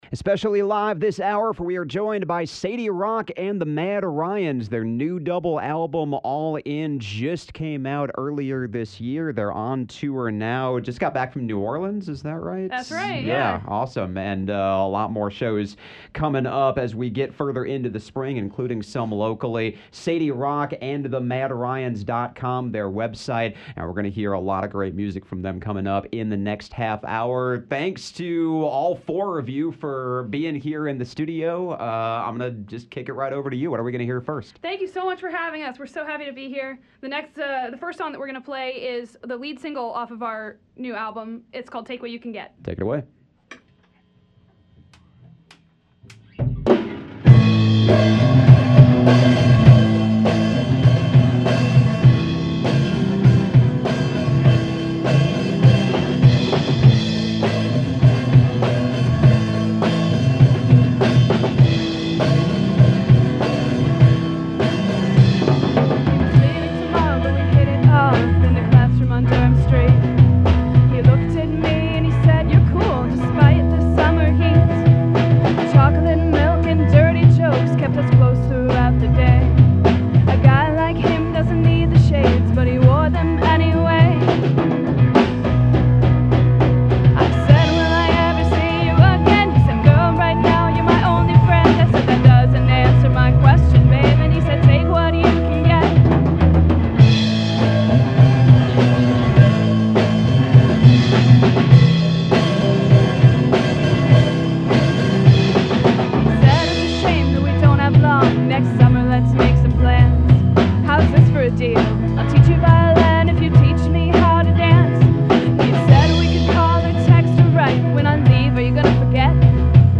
vox, guitar
drums